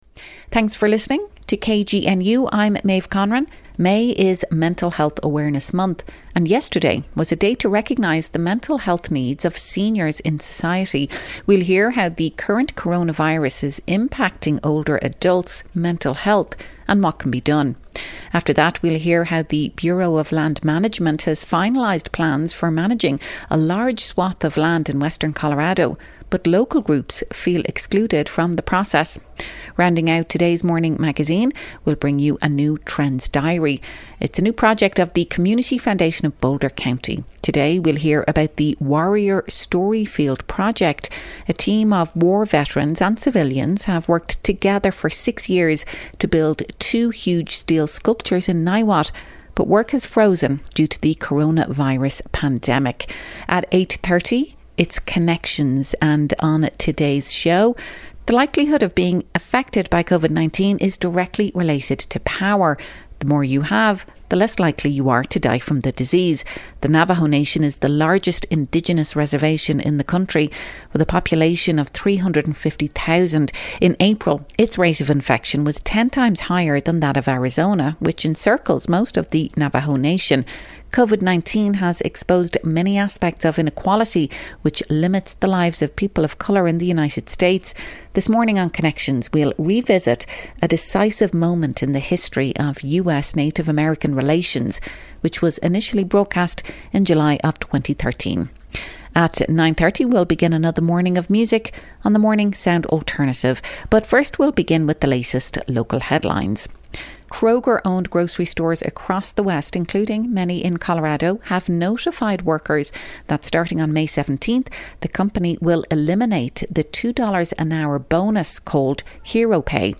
The Morning Magazine features local news headlines, stories and features and broadcasts on KGNU Monday through Friday 8.04-8.30am.